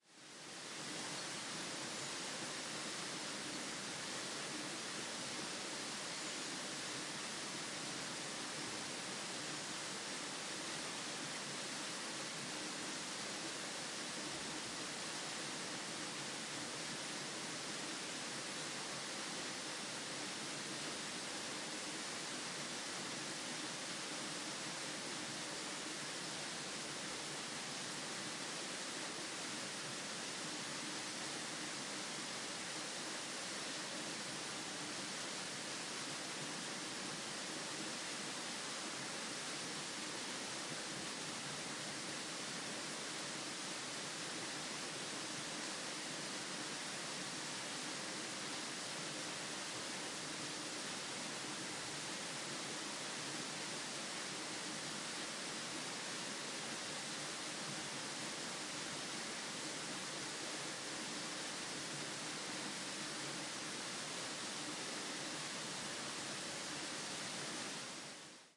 现场记录。水 " 远处森林中的瀑布
描述：使用内置麦克风的Zoom H1录制的Wav文件。瀑布在从远处记录的森林里。在提契诺（Tessin），瑞士。
Tag: 现场录音 河流 溪流 氛围 环境 森林 fieldrecording 瑞士 瀑布 和平 自然 放松 小溪 提契诺州 森林 提契诺